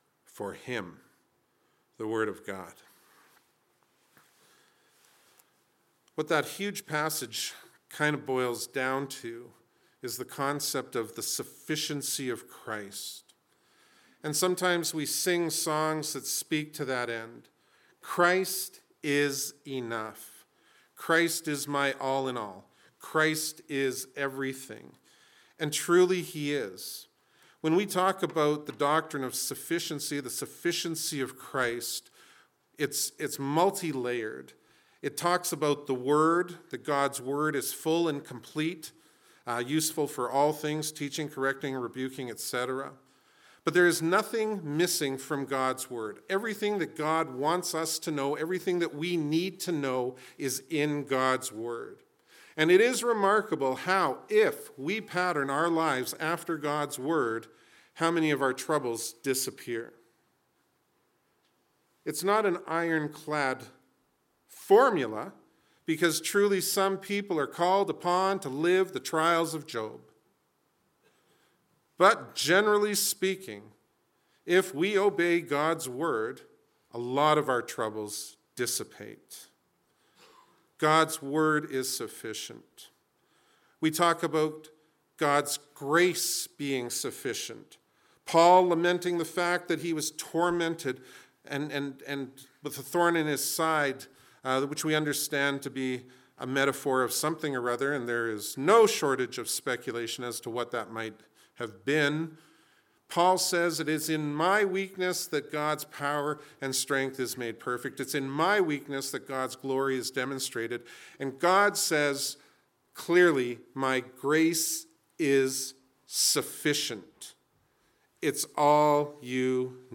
Nothing but the Blood – Hillview Baptist Church